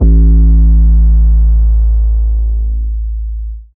TM88 Surprise808.wav